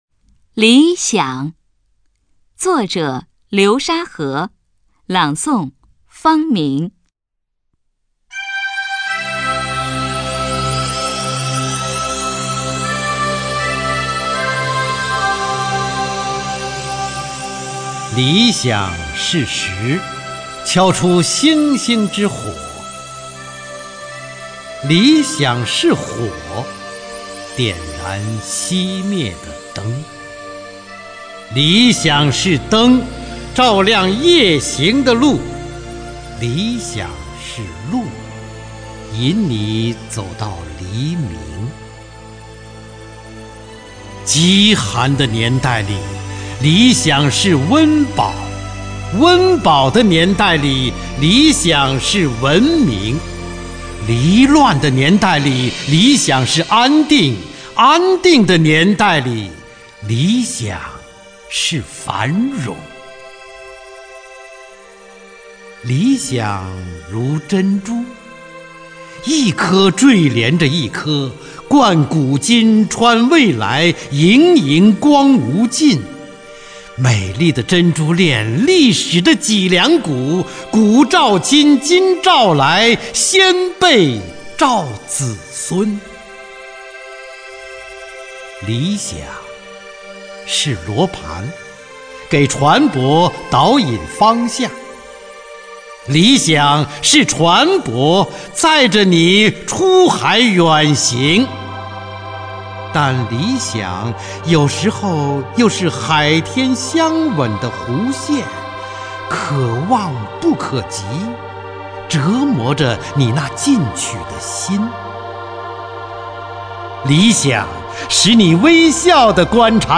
[27/7/2011]方明配乐朗诵诗人流沙河的作品《理想》
朗诵 方  明
中央人民广播电台原版录音